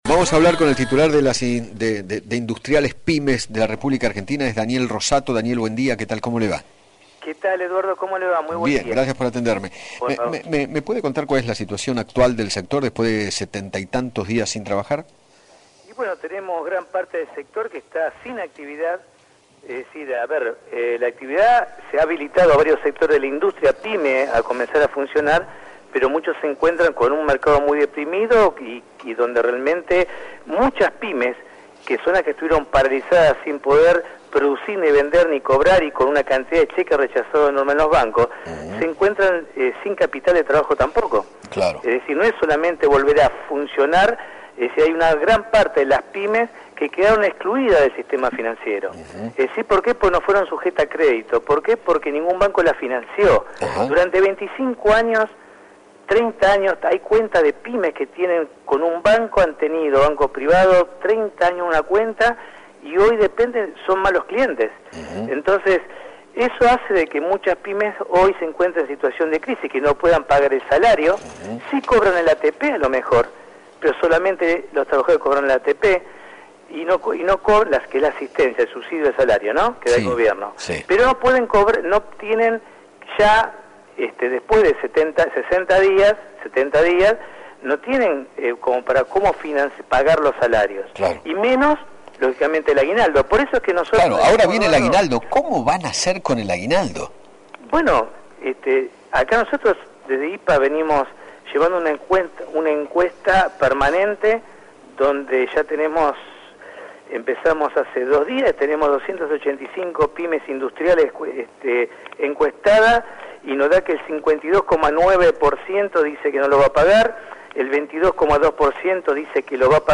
dialogó con Eduardo Feinmann sobre la situación actual del sector